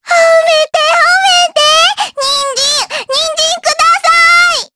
Luna-Vox_Victory_jp.wav